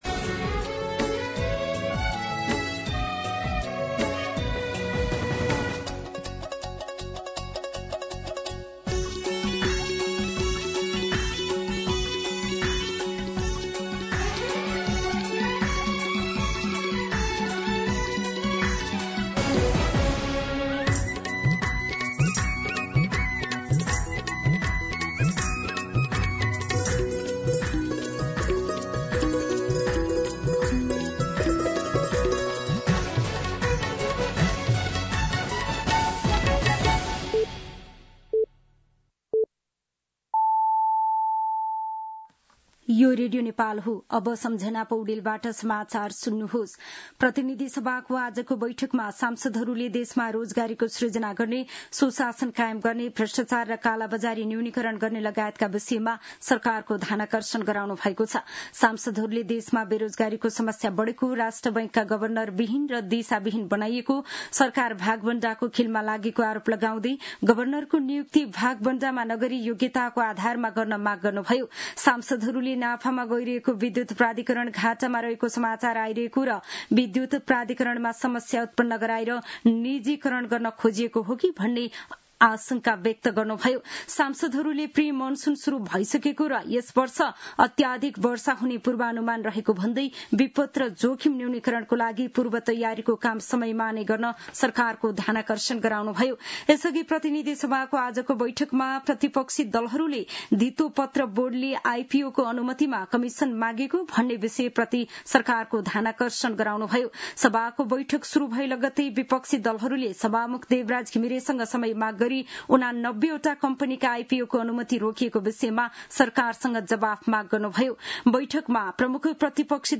An online outlet of Nepal's national radio broadcaster
दिउँसो ४ बजेको नेपाली समाचार : २४ वैशाख , २०८२